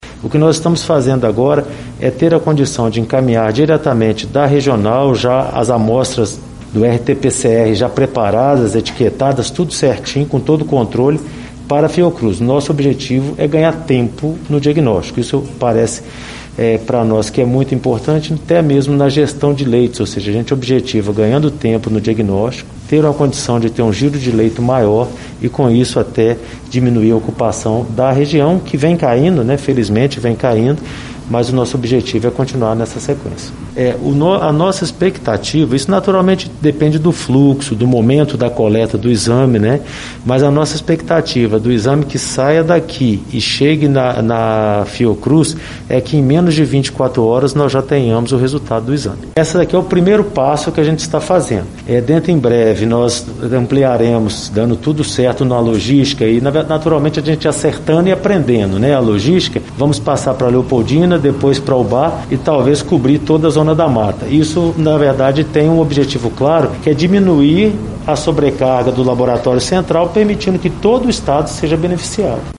O anúncio foi feito pelo secretário de Estado de Saúde, Carlos Eduardo Amaral, em coletiva nesta sexta-feira, 12, em Juiz de Fora.